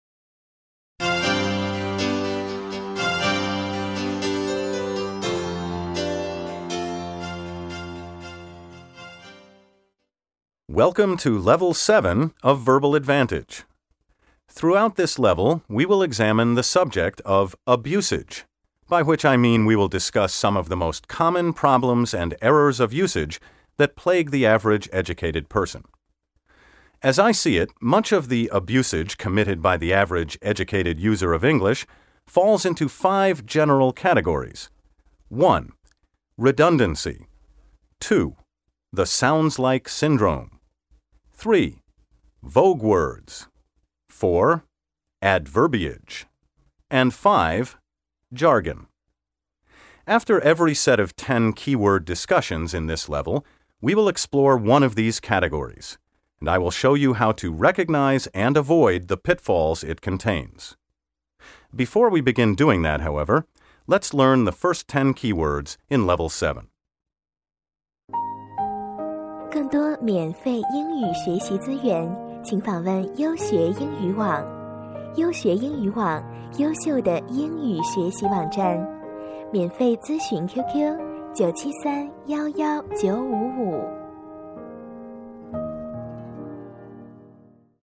音乐：布伦德尔（Alfred Brendel）演奏的莫扎特《G大调钢琴独奏曲，K.533》（实际涵盖 K.533 与 K.494）